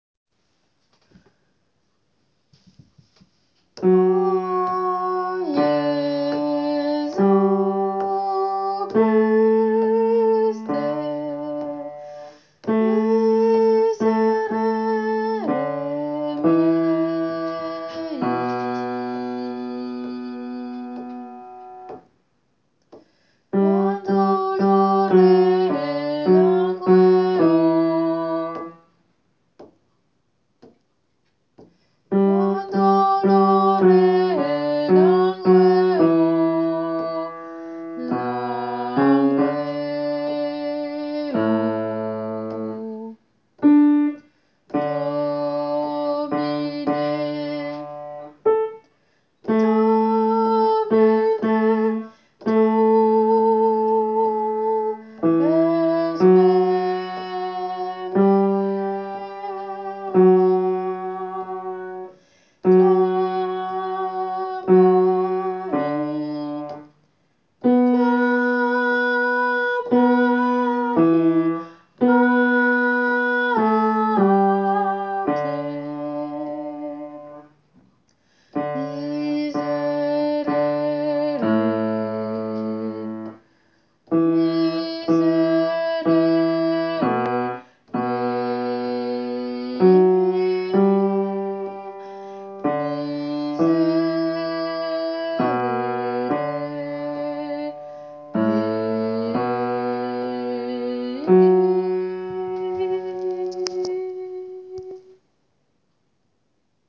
Basse :
jesu-christe-basse.wav